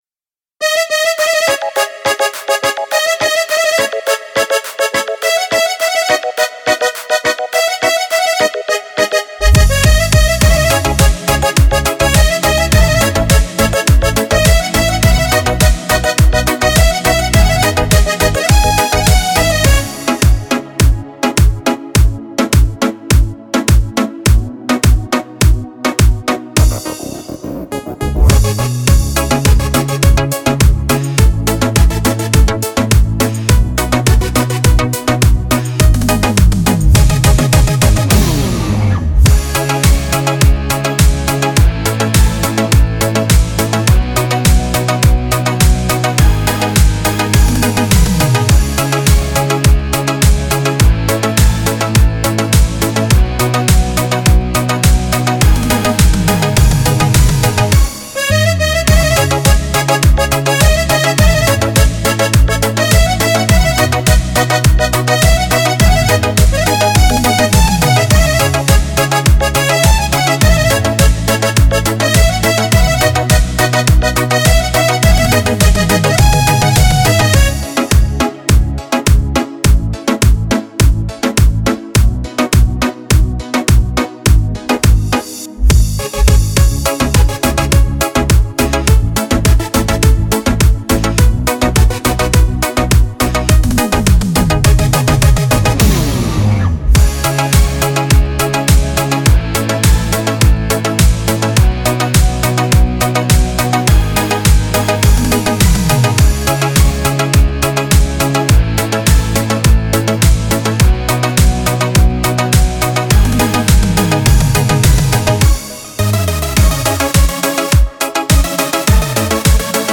Минусовки